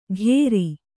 ♪ ghēri